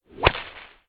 Diesen Überschallknall (engl. sonic boom) hörst du auch beim korrekten Schlagen mit einer Peitsche, wenn das Ende der Peitsche die Schallmauer durchbricht (Hörbeispiel
Whip-sound.ogg